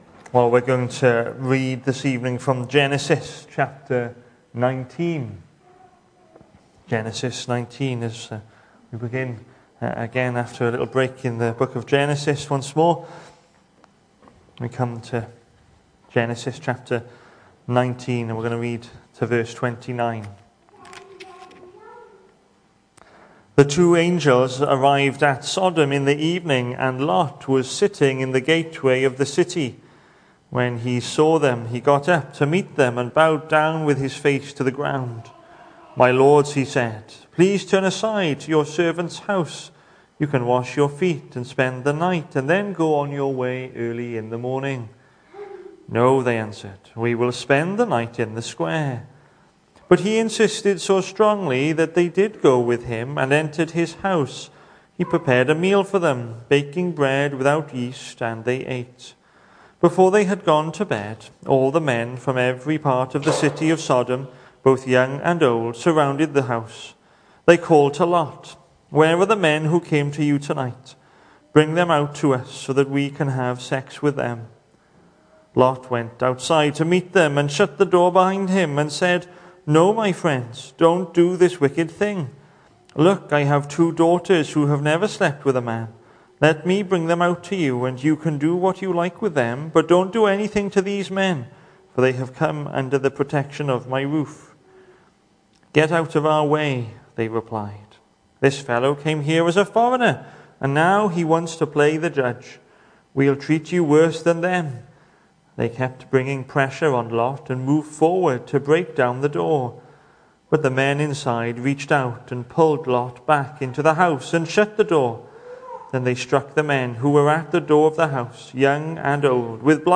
Hello and welcome to Bethel Evangelical Church in Gorseinon and thank you for checking out this weeks sermon recordings.
The 18th of January saw us hold our evening service from the building, with a livestream available via Facebook.